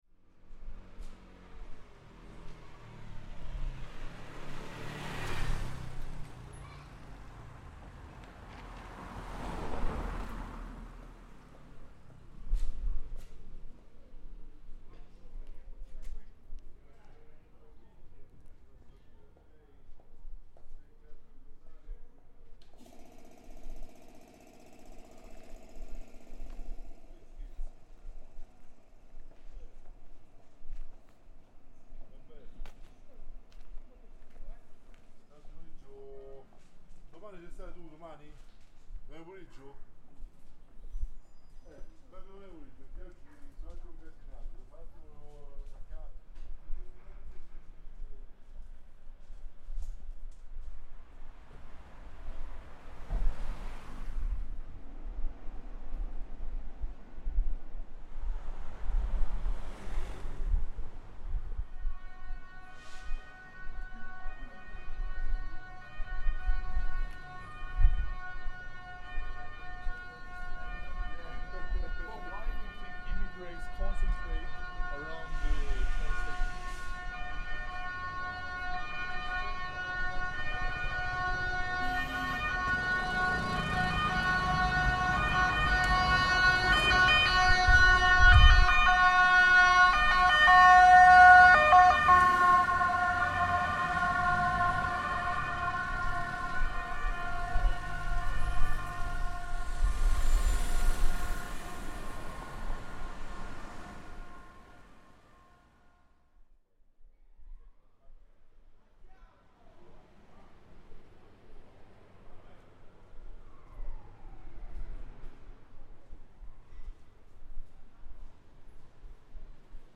a worker uses a jackhammer
ambulance passing close
in front of the university child neuropsychiatry building, students talk in English about immigration
a man and a woman are talking about someone who has been rude or mean to them or to someone else
His accent is the one we call "romano verace" (truly Roman) 4:00 the water noise comes from one of the typical Roman public drinking fountains, called "nasone" (big nose) 4:35 a homeless woman, in full breakdown, starts shouting, crying and swearing in her mother language. She walks frenetically on the road and you can hear her incredibly quick steps: it's not possible to hear that, but part of the rapidity is also due to her shortness, shorter legs = quicker pace.